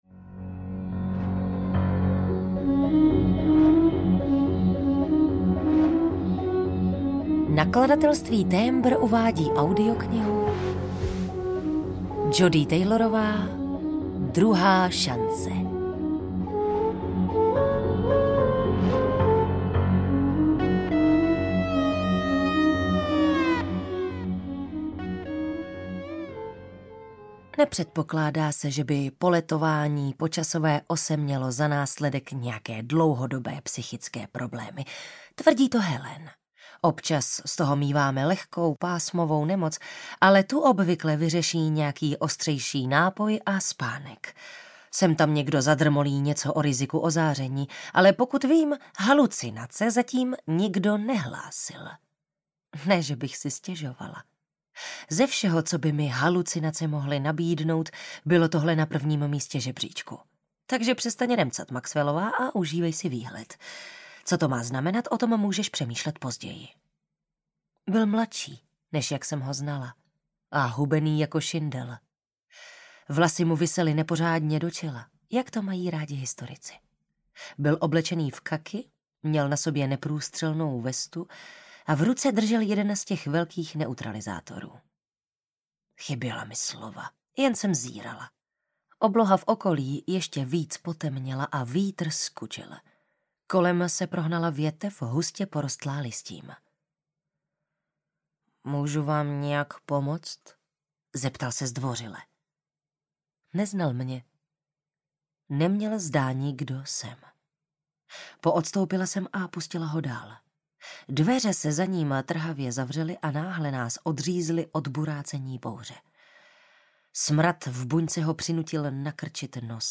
Druhá šance audiokniha
Ukázka z knihy